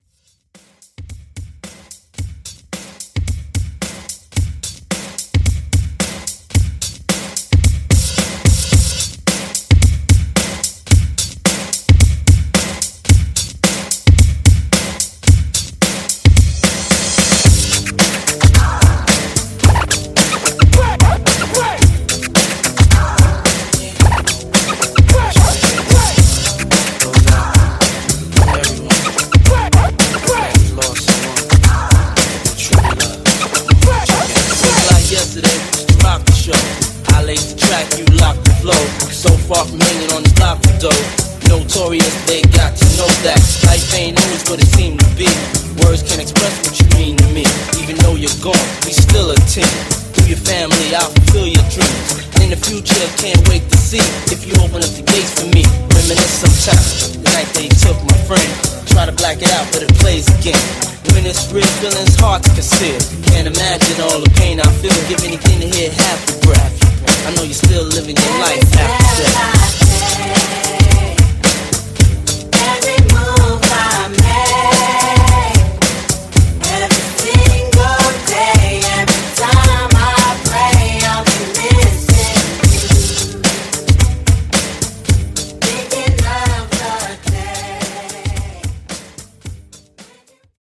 90’s Rap Re-Drum)Date Added